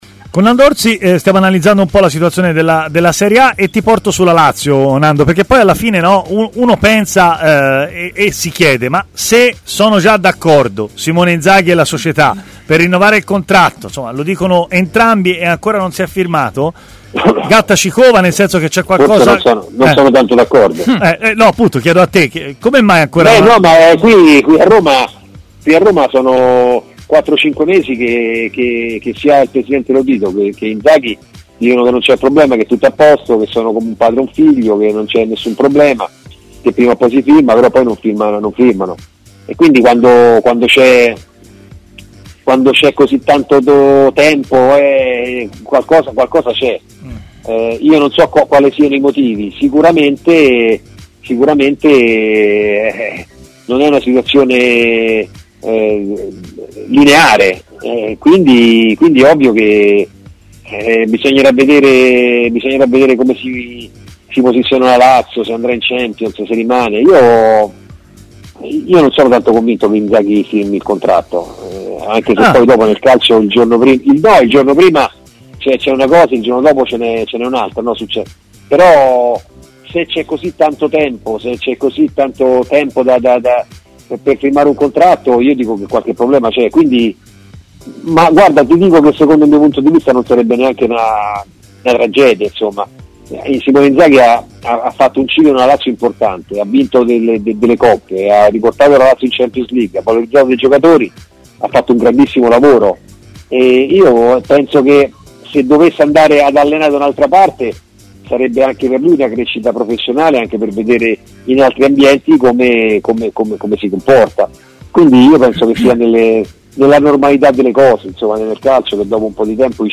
L'ex portiere biancoceleste è intervenuto ai microfoni di TMW Radio per parlare del possibile rinnovo di contratto di Simone Inzaghi